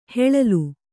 ♪ heḷalu